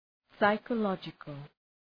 {,saıkə’lɒdʒıkəl}
psychological.mp3